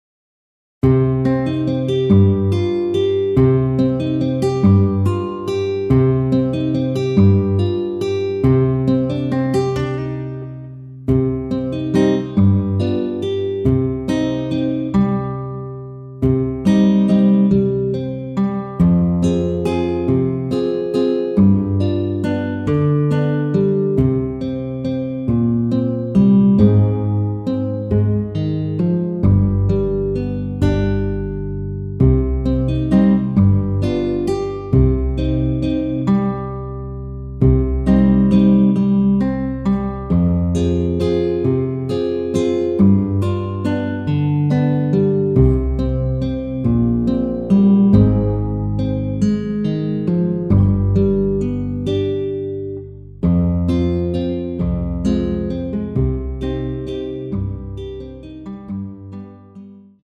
원키에서(-1)내린 MR입니다.
Cm
◈ 곡명 옆 (-1)은 반음 내림, (+1)은 반음 올림 입니다.
앞부분30초, 뒷부분30초씩 편집해서 올려 드리고 있습니다.